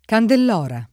Candelora [kandel0ra] (meno com. Candelara [kandel#ra] e Candelaia [kandel#La]; ant. Candellora [